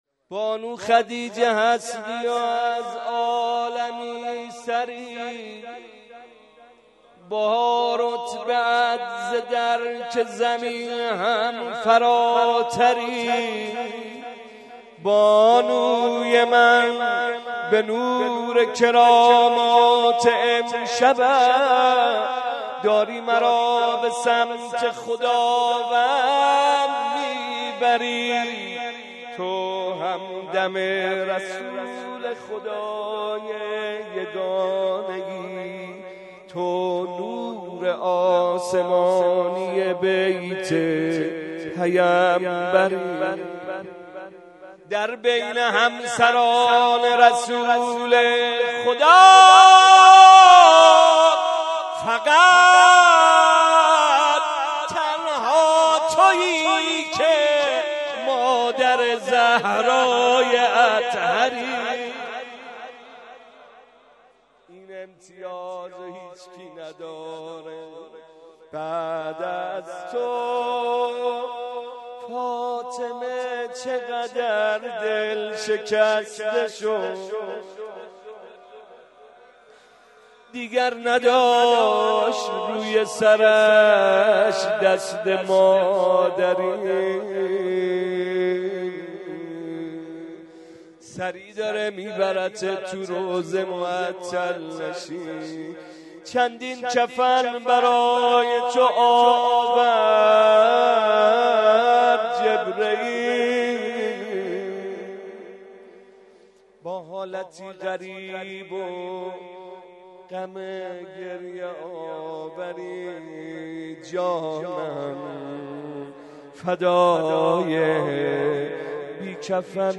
مناسبت : شب یازدهم رمضان
قالب : روضه